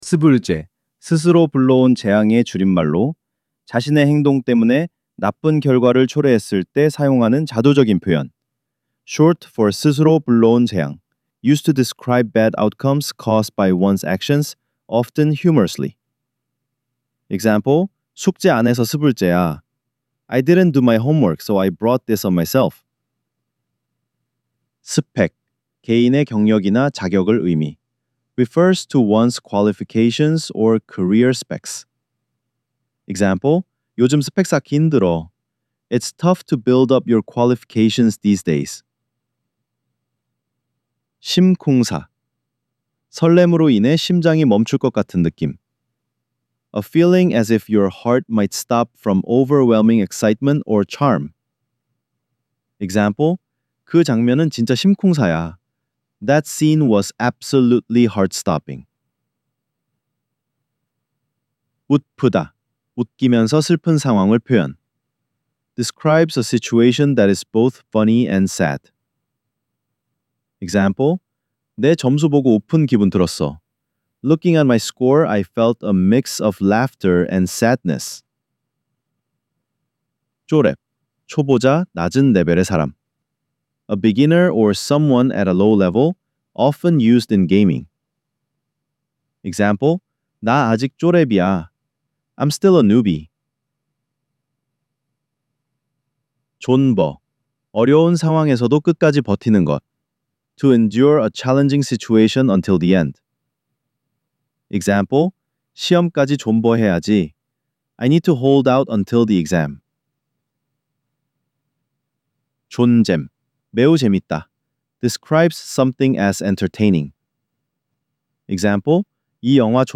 6) Korean Slang Decoded: Vol. 06 – Audio Narration